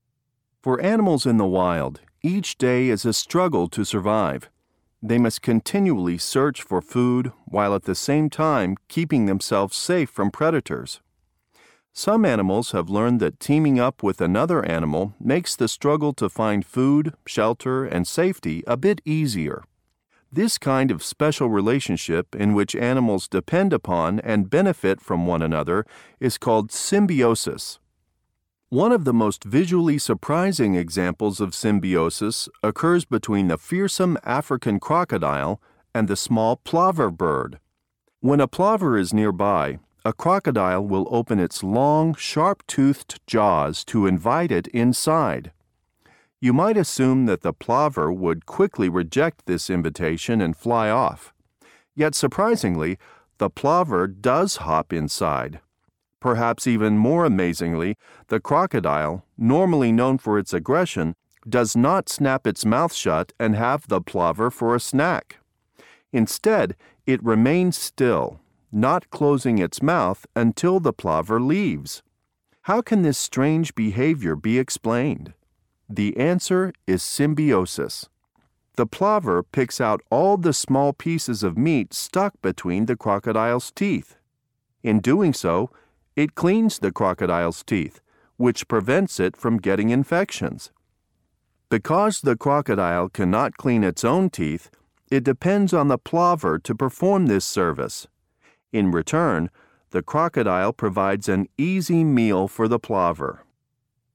قطعه قرائيه جاهزه للصف الثالث الثانوي ف1 الوحده الاولي mp3